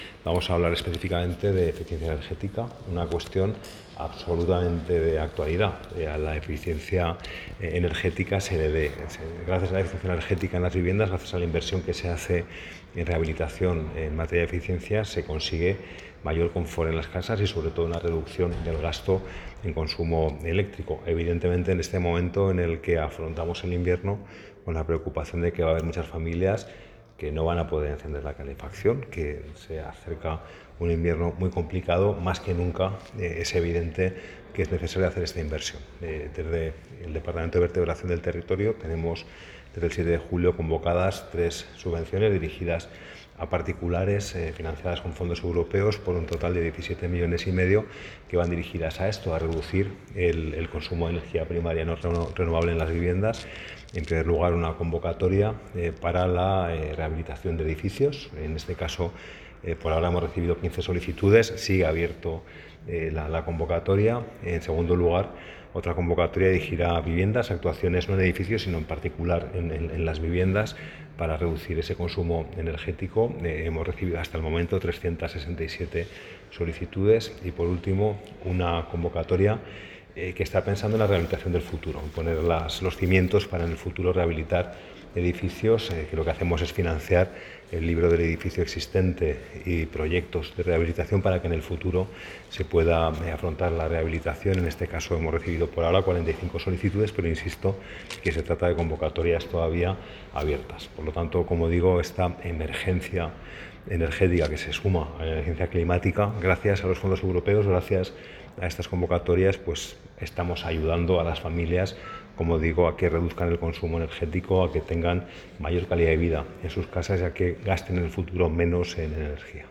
José Luis Soro. Mesa de trabajo sobre rehabilitación